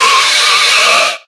Cries
WHIRLIPEDE.ogg